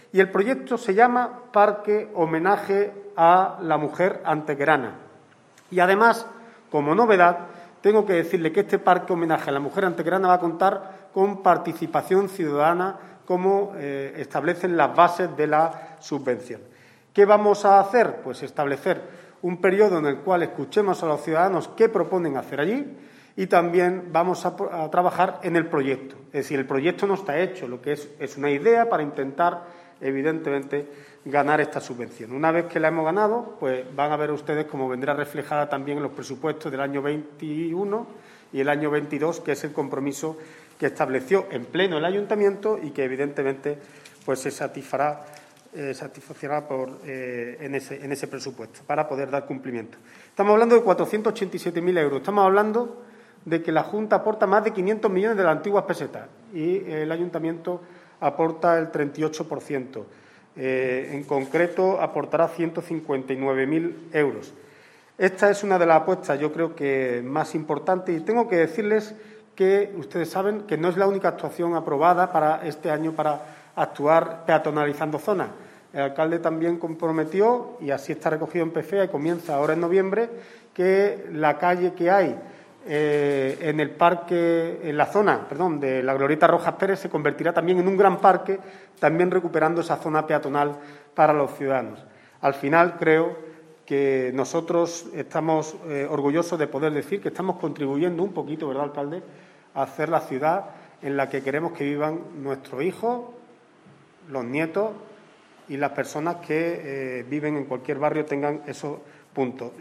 El alcalde de Antequera, Manolo Barón, y el concejal delegado de Obras, José Ramón Carmona, han presentado han informado en la mañana de hoy viernes en rueda de prensa de la puesta en marcha del proyecto para la creación de un nuevo parque homenaje a las mujeres antequeranas que estará enclavado en el área residencial conocida como "Parque Verónica".
Cortes de voz